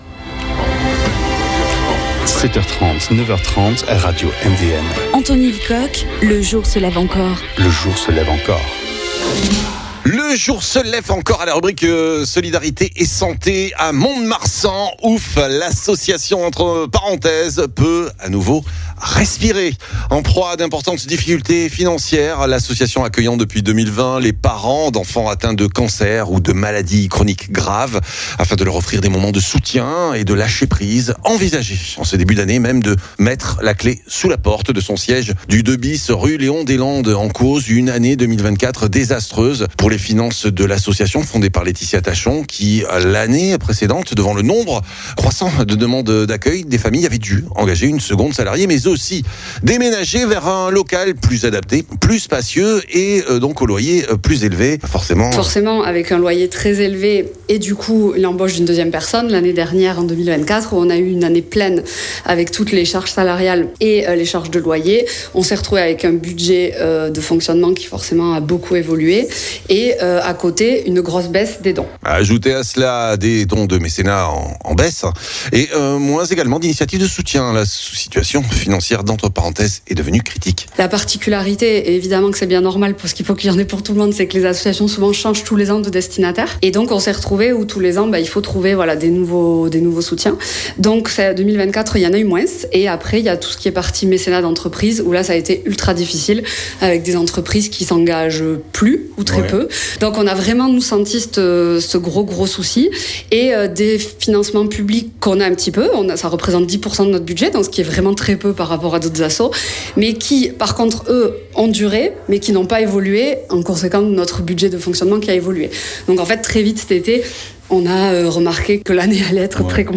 Reportage auprès des parents et bénévoles.